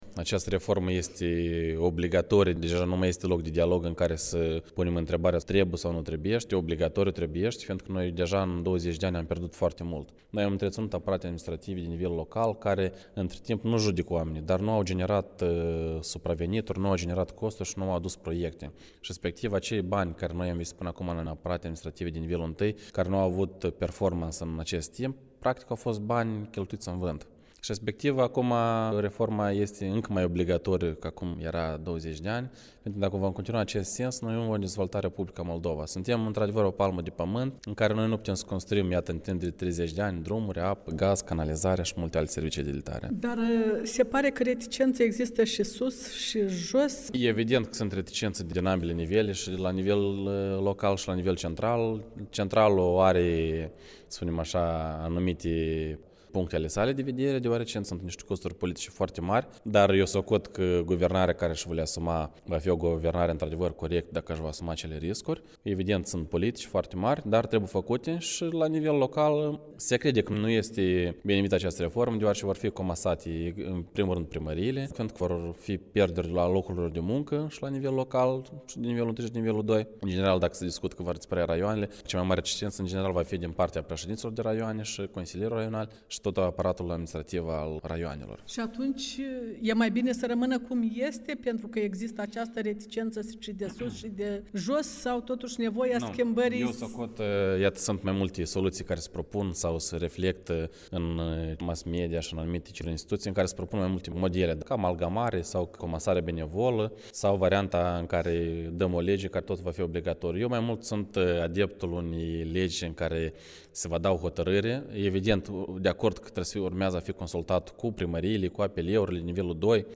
Interviu cu Leonid Boaghi, primarul satului Sireți, raionul Strășeni